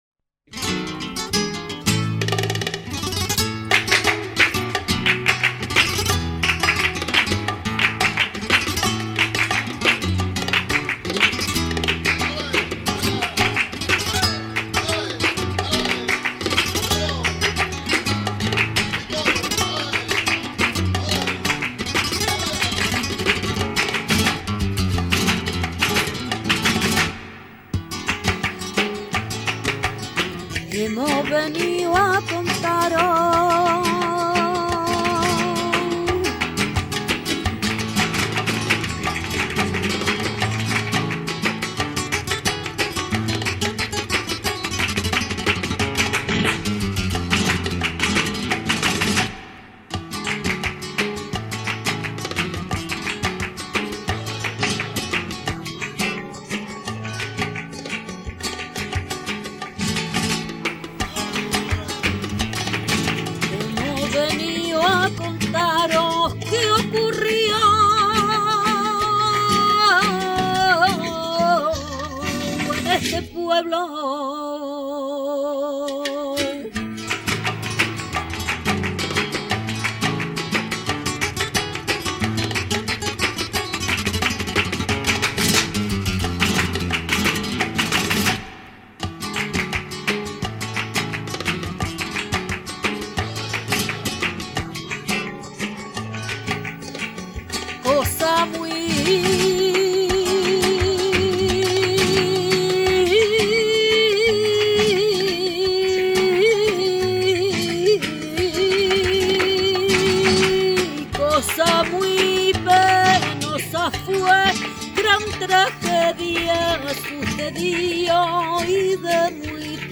Flamenco clásico: COLOMBIANAS